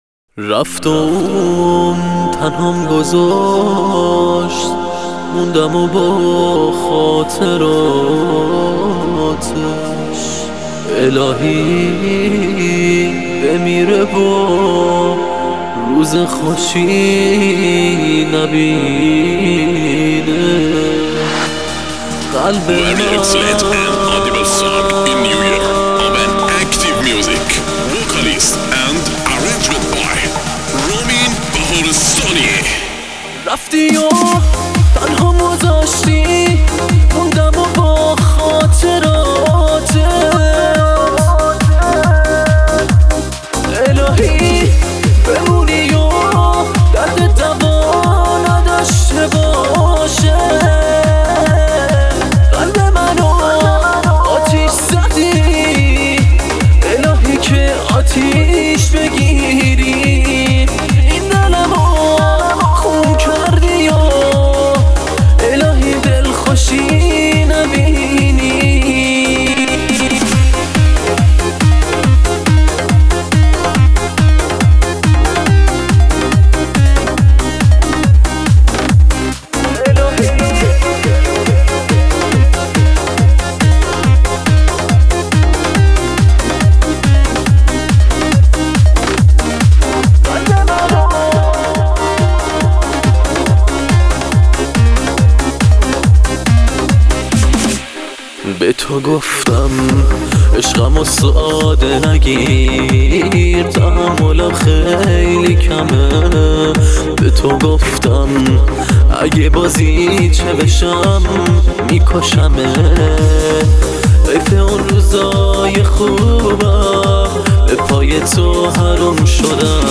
آهنگ جدید و بسیار غمگین و اکتیو